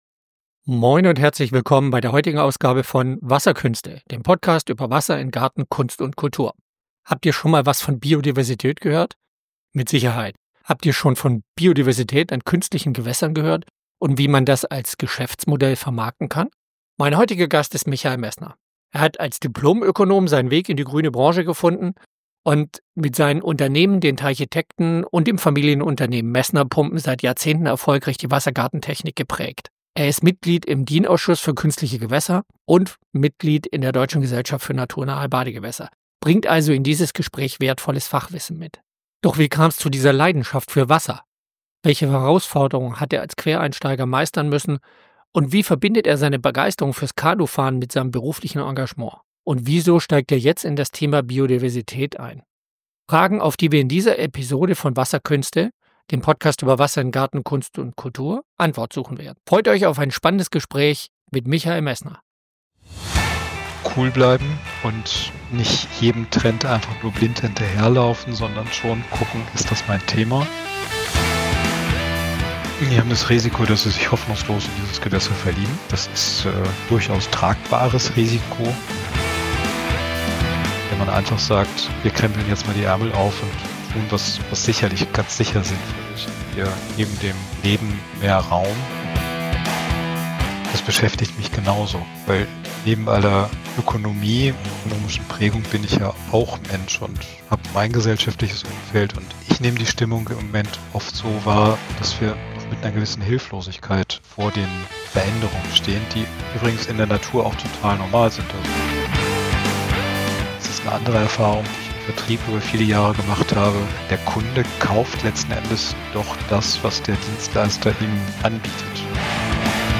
Ein inspirierendes Gespräch über die Verbindung von Ökonomie und Ökologie im Umgang mit Wasser.